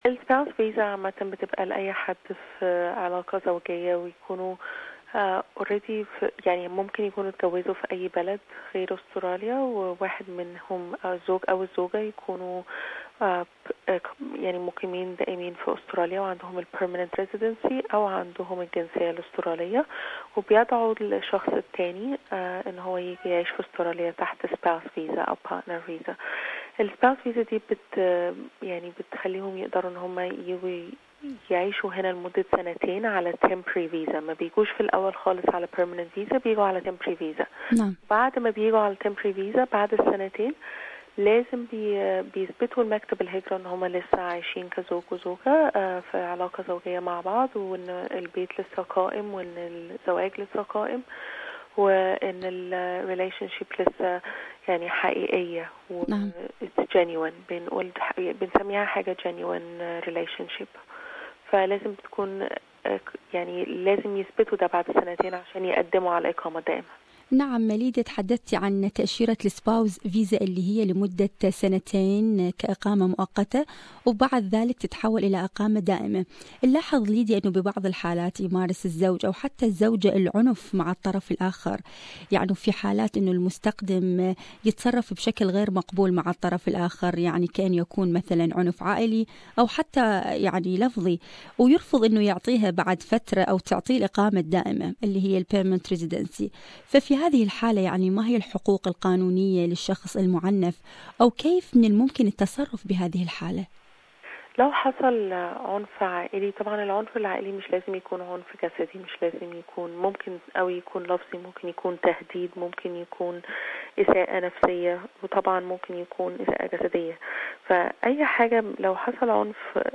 لقاءِ